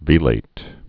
(vēlāt, -lĭt)